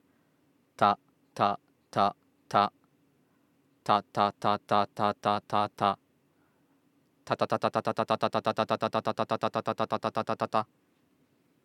「た」を発音して舌の動きを改善する
音量注意！
lateral-lisp-03.mp3